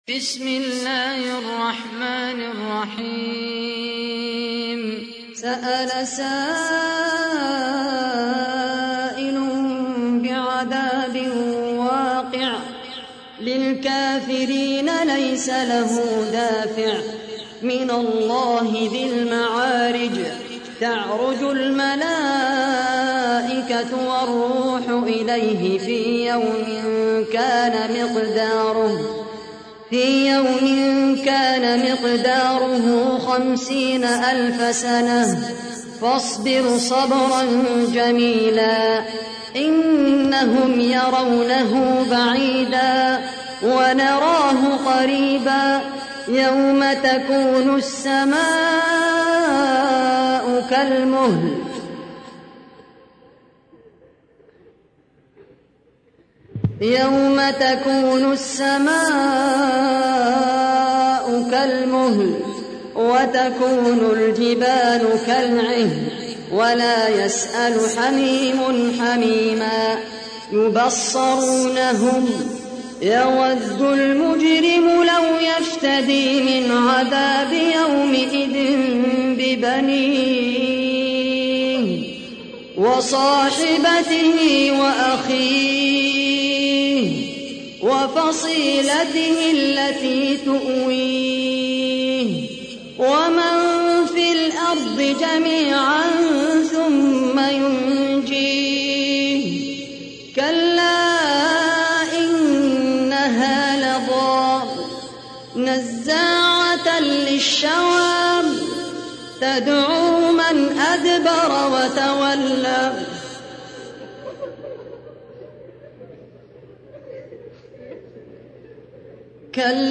تحميل : 70. سورة المعارج / القارئ خالد القحطاني / القرآن الكريم / موقع يا حسين